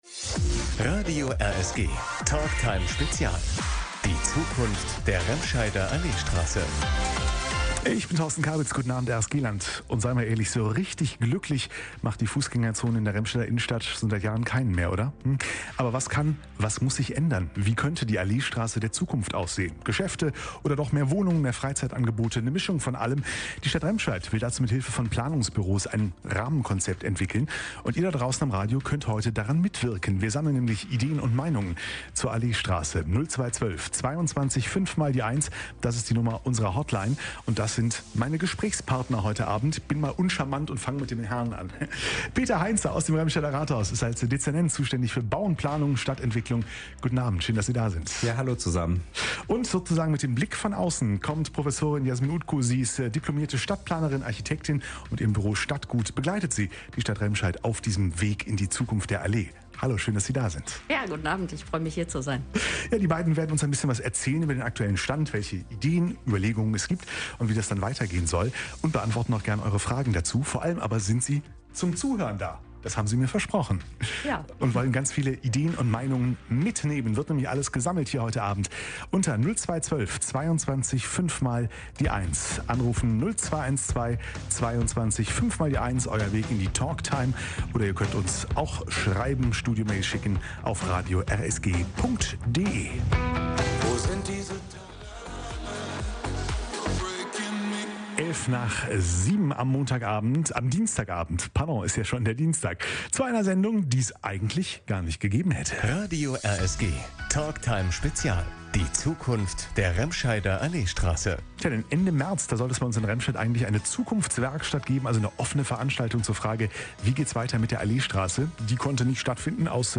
Ihr konntet dazu während der Sendung anrufen.